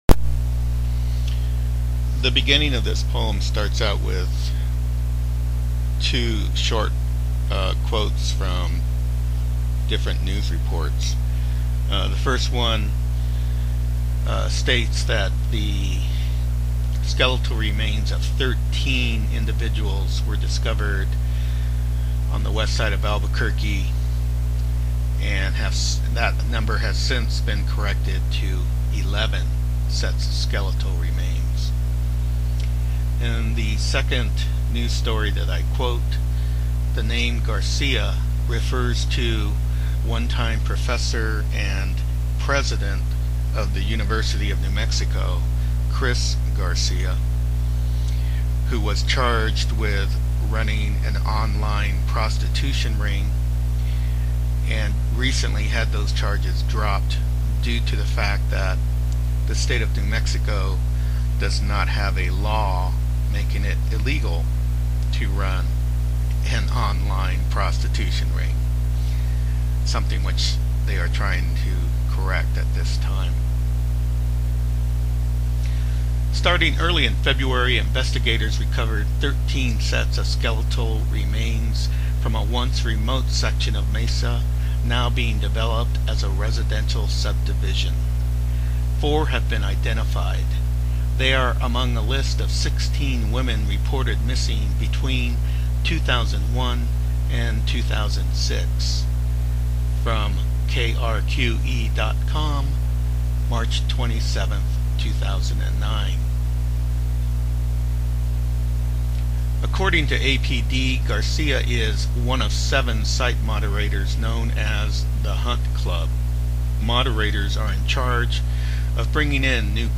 read this poem